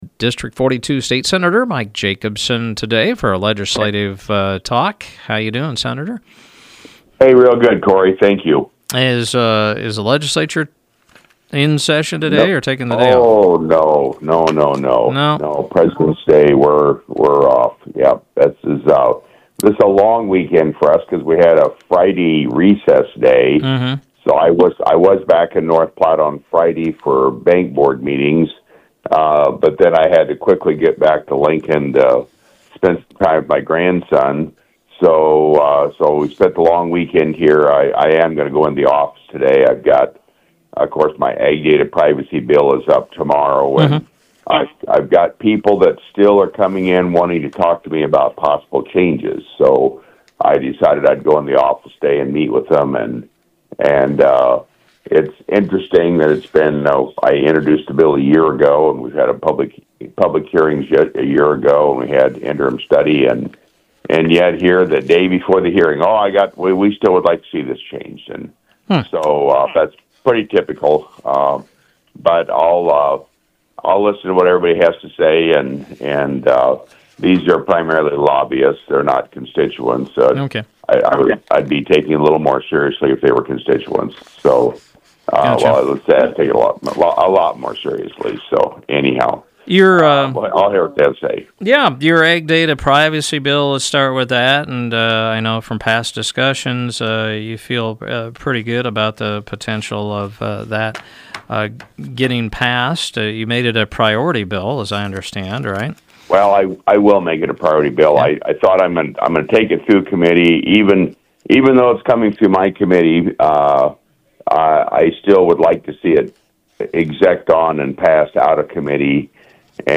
District 42 State Senator Mike Jacobson was a guest on Mugs Monday, and gave updates on his rail park bill and the legislature’s reprimand on Senator Machaela Cavanaugh.